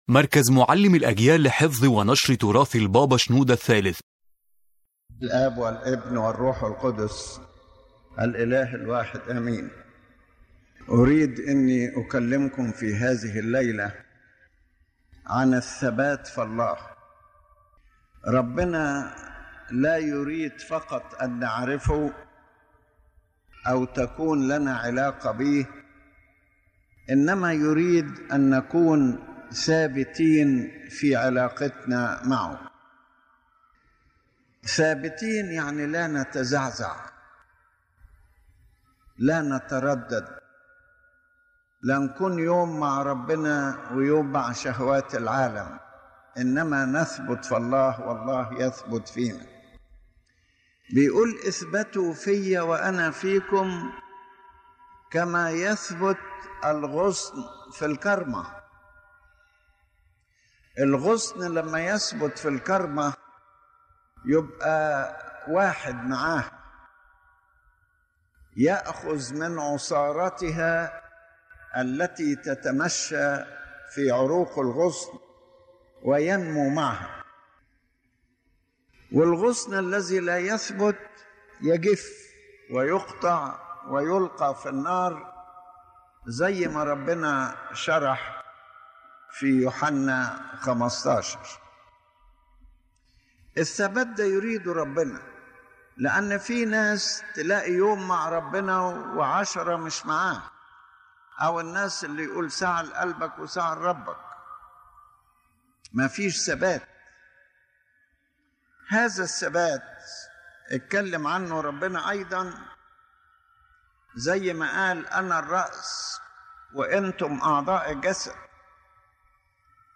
The lecture discusses the importance of steadfastness in the relationship with God, not just as knowledge or temporary connection, but as a stable life that does not shake before circumstances or trials.